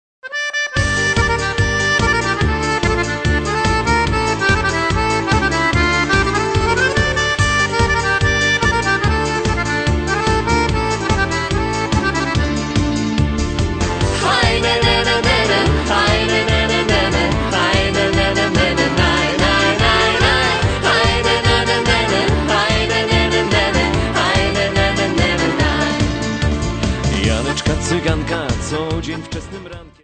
3 CD Set of Polish Gypsy Music.